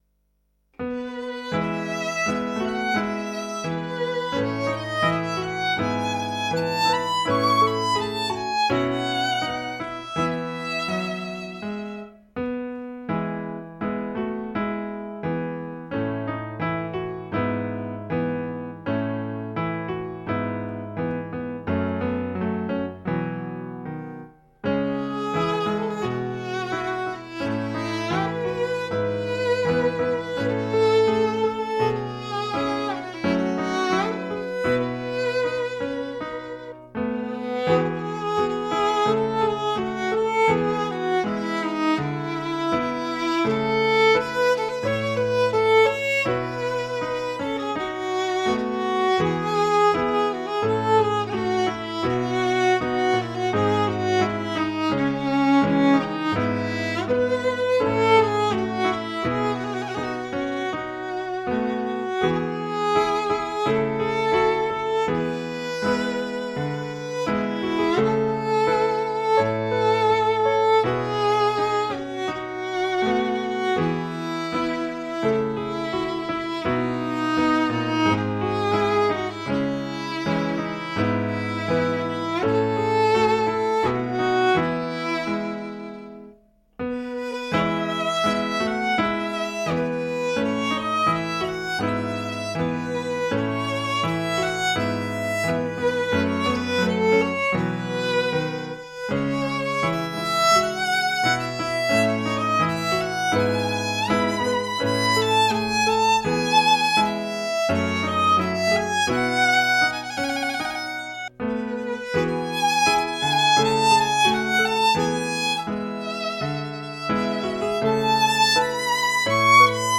hymn-Rybna-instrumentalna.mp3